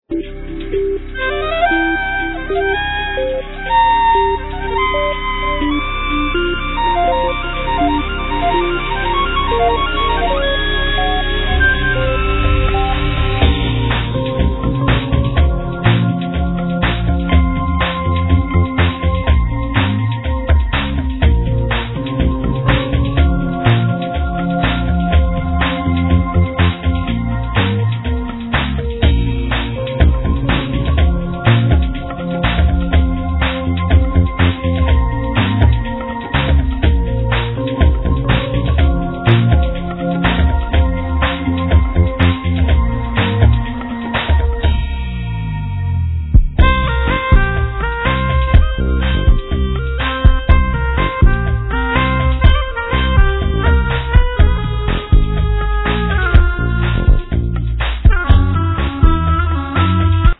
Saxophone, Synthsizer
Otera, Utogardon, Mouth harp